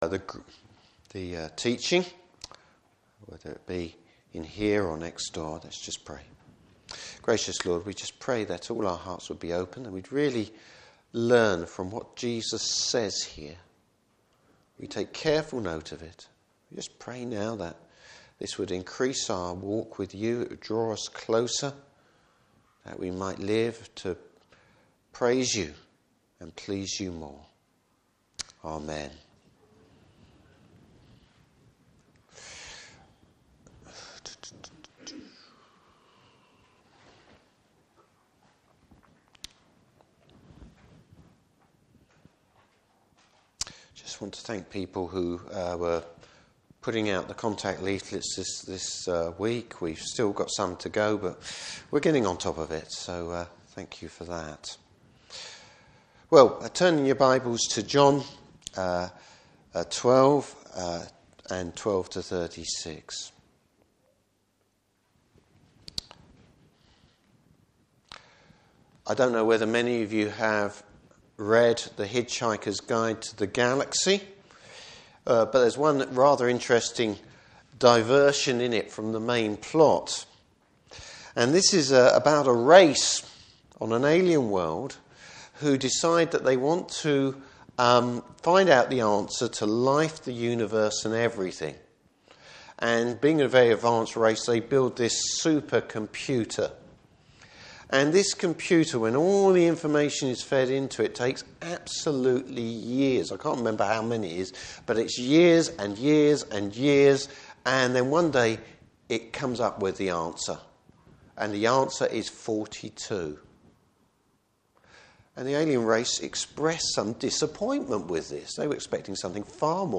Service Type: Morning Service Bible Text: John 12:12-36.